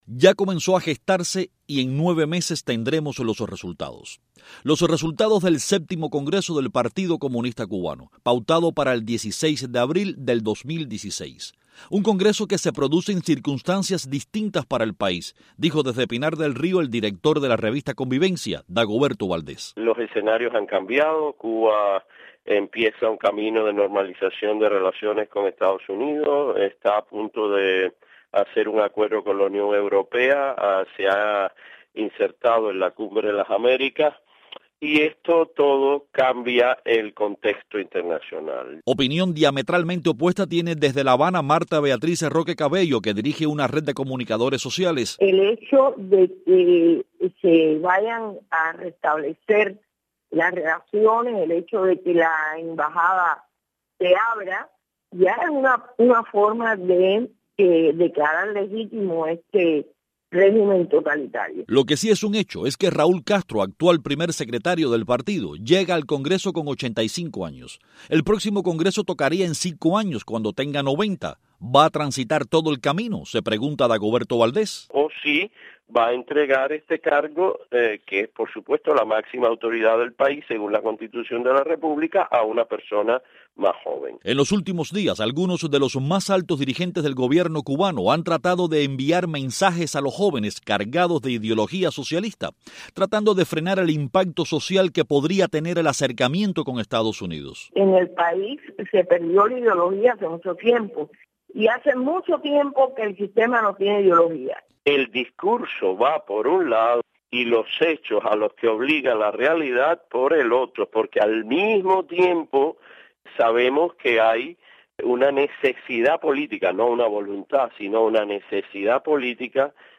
Noticias de Radio Martí